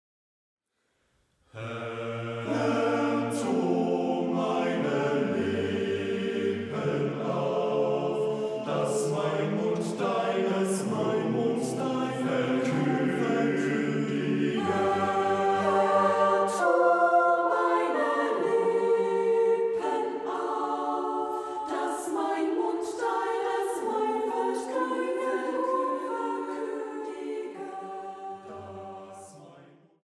Marienmusik aus dem evangelischen Dom St. Marien zu Wurzen
Orgel